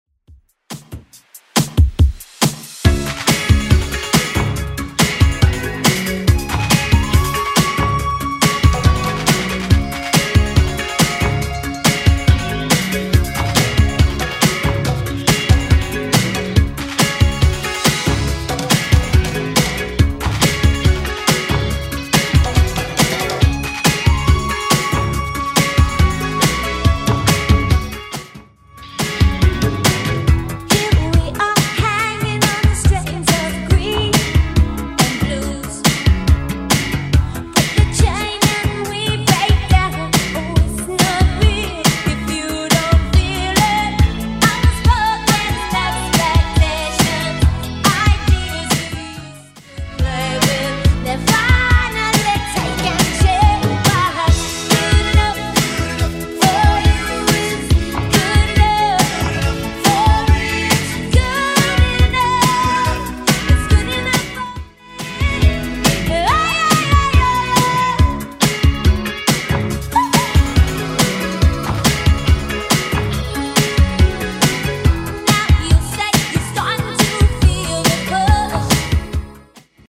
Genre: 70's
BPM: 124